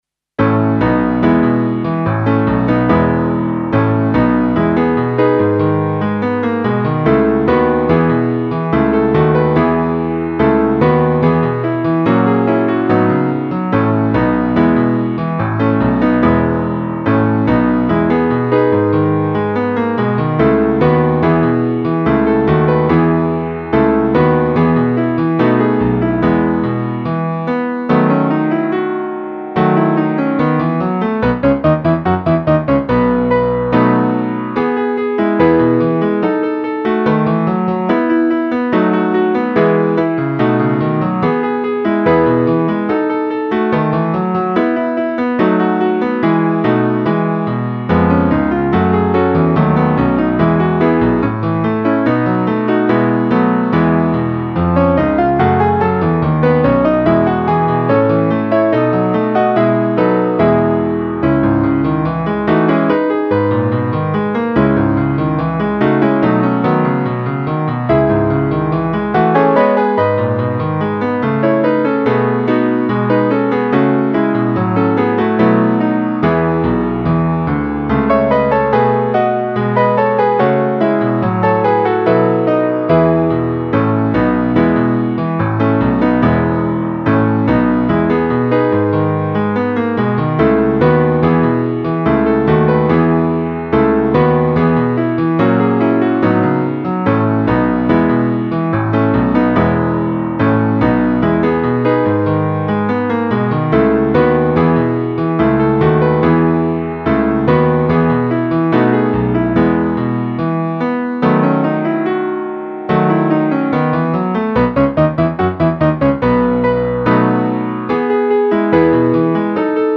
(Base Mp3)
la danza dellle nore base.mp3